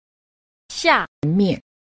5. 下面 – xiàmiàn – hạ diện (hạ giới)